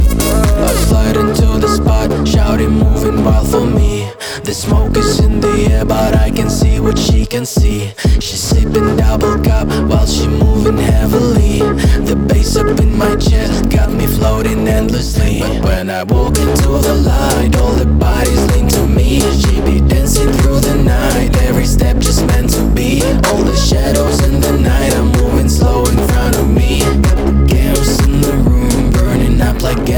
Жанр: Танцевальные / Электроника
Electronic, Dance